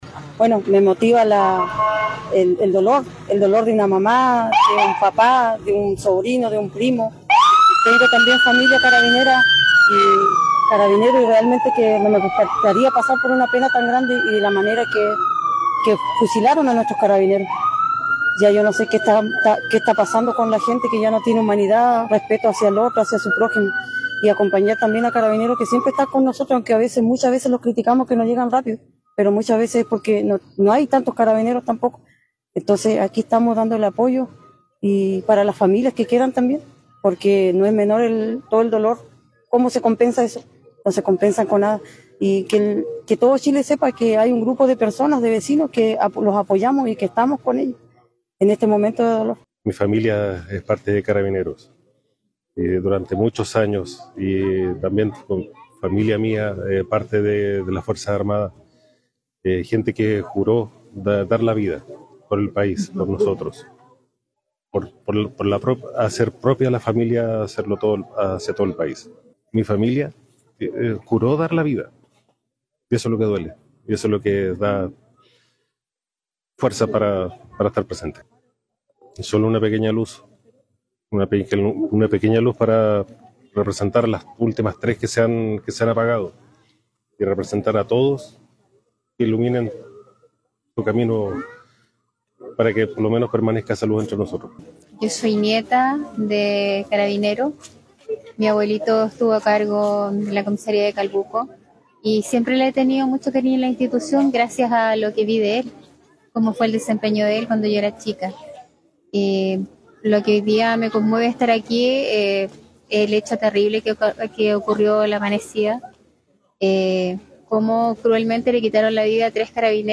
29-CEREMONIA-POR-CARABINEROS.mp3